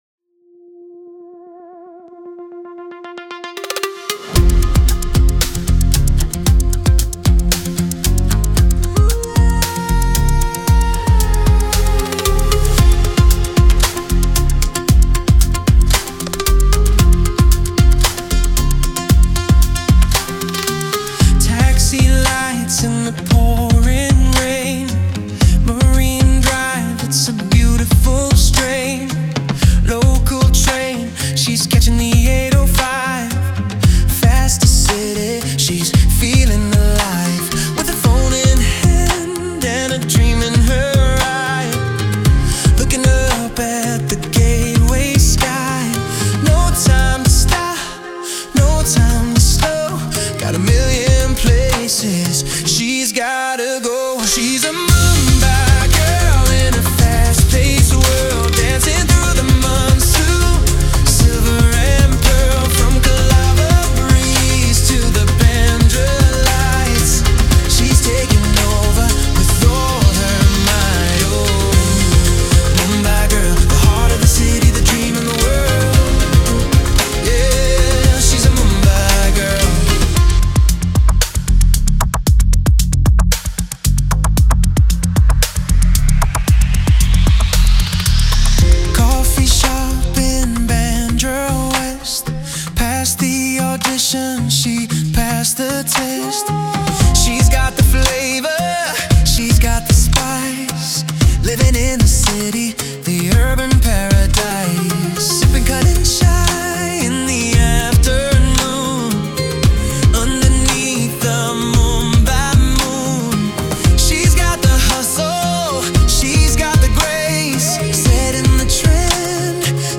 Pop - 4:12 Min.